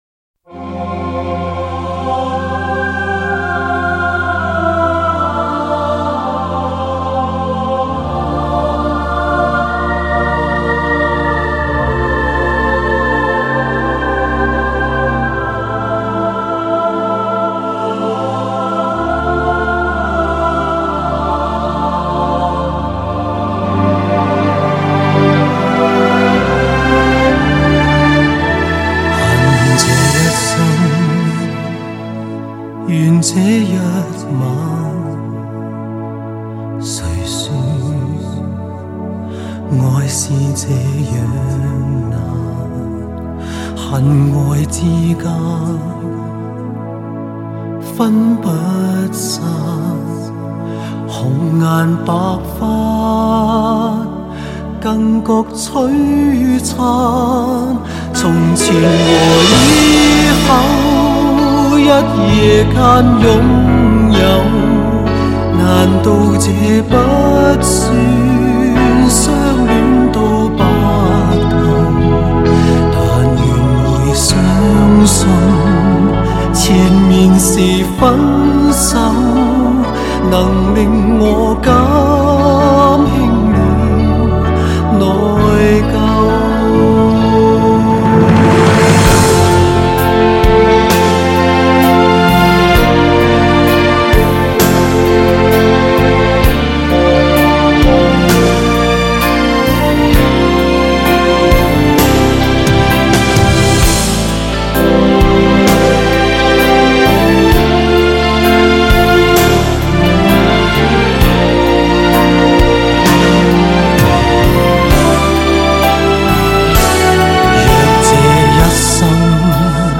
专辑类别：录音室专辑
专辑风格：国语流行 Mandarin Pop